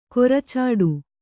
♪ koracāḍu